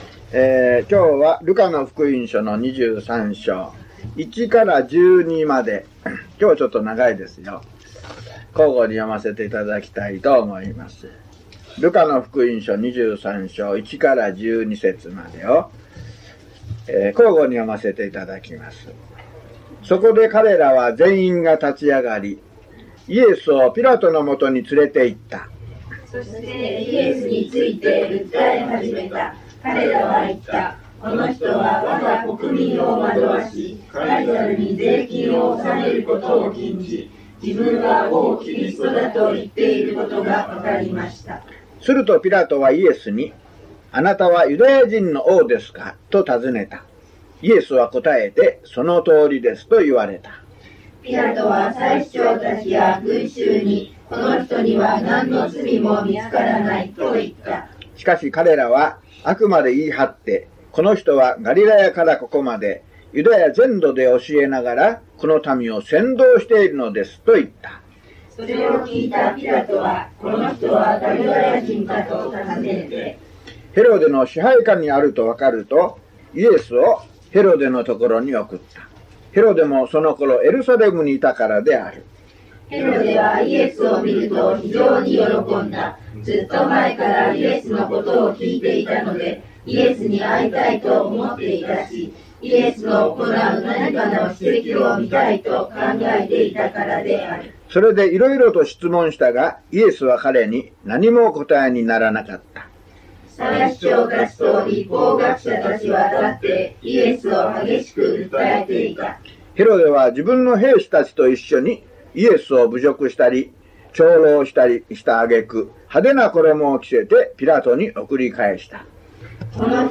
(途中で途切れています）